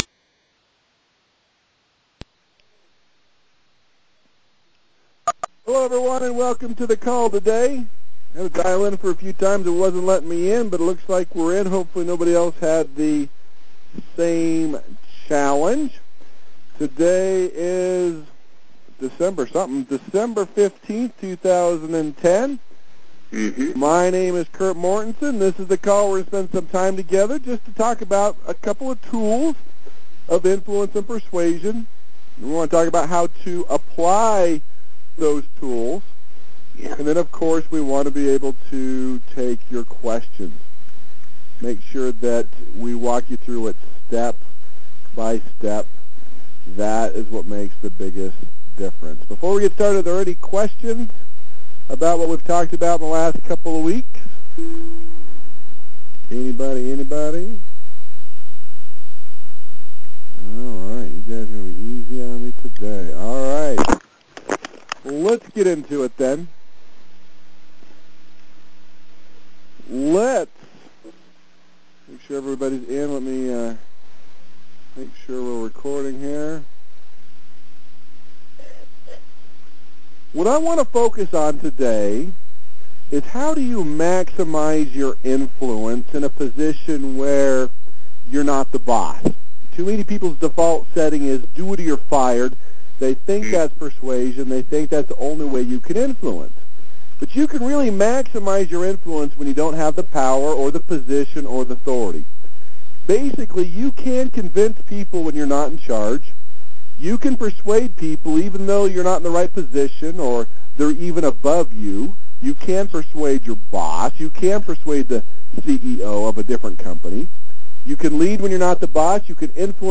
‹ Individual influence Information marketing › Posted in Conference Calls